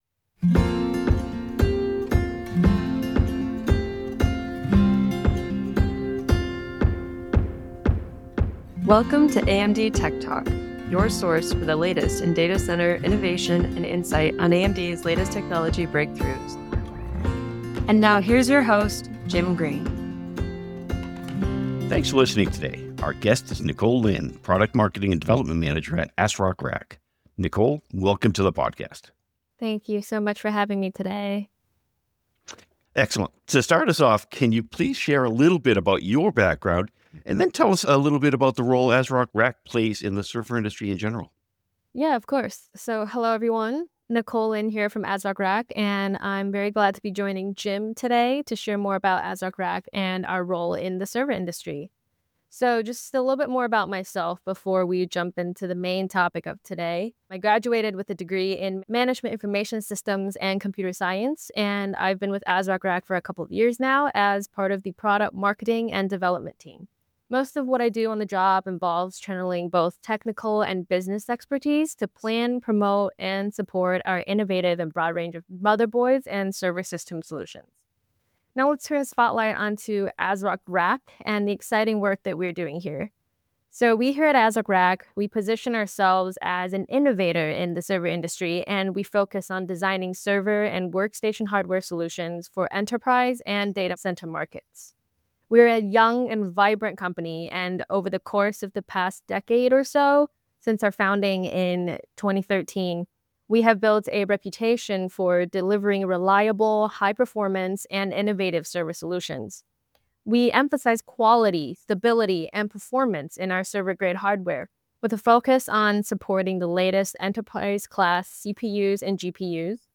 AMD TechTalk features discussion on servers, cloud computing, AI, HPC and more.